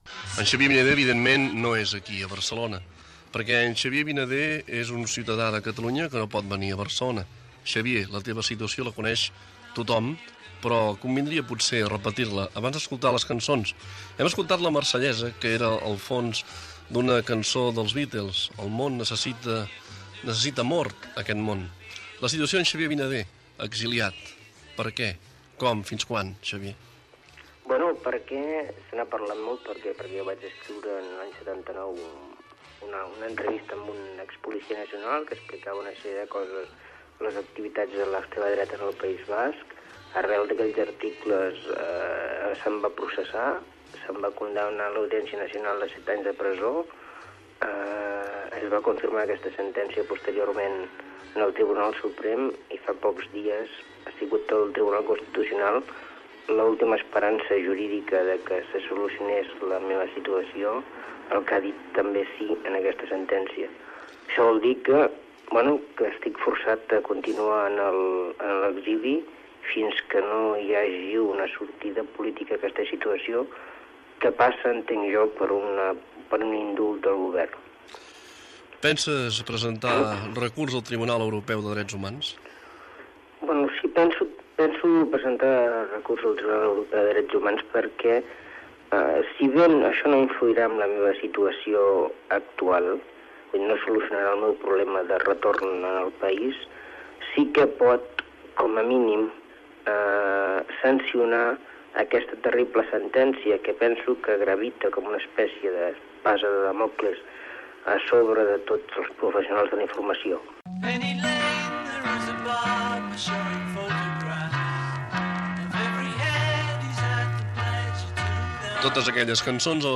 Entrevista telefònica
Musical